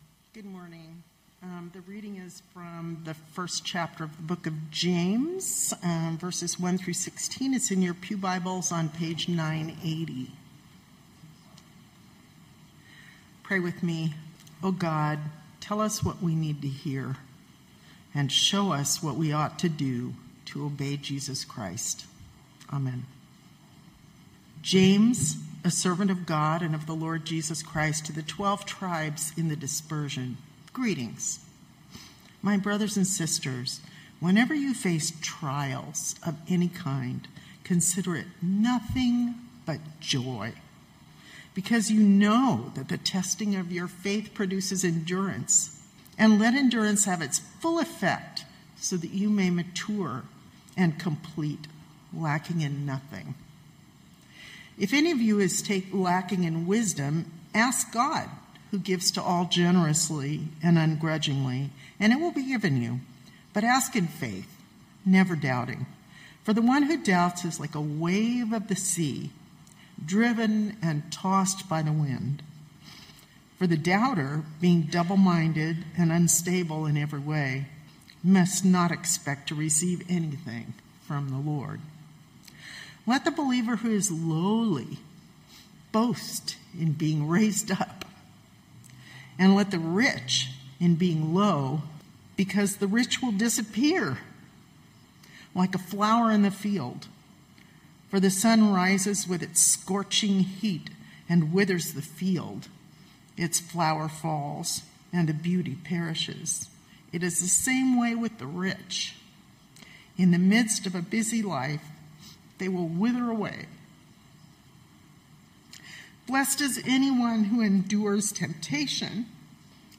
Knox Pasadena Sermons The Trial's Delight Jun 29 2025 | 00:23:42 1x 00:00 / 00:23:42 Subscribe Share Spotify RSS Feed Share Link Embed